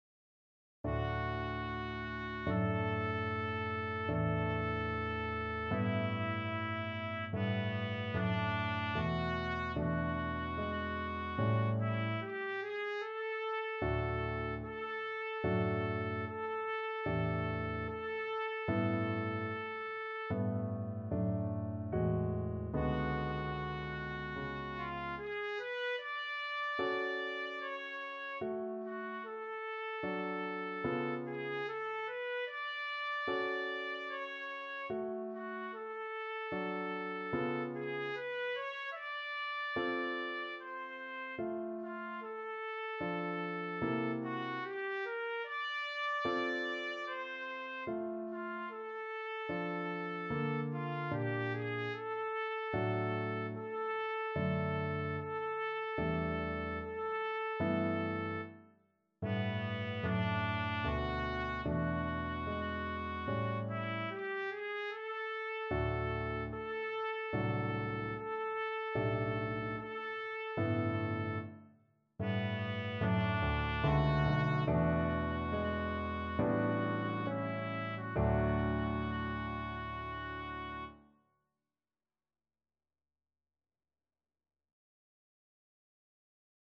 Classical Liszt, Franz Consolation No.1 in E Trumpet version
Trumpet
D major (Sounding Pitch) E major (Trumpet in Bb) (View more D major Music for Trumpet )
B4-E6
4/4 (View more 4/4 Music)
Andante con moto =74 (View more music marked Andante con moto)
Classical (View more Classical Trumpet Music)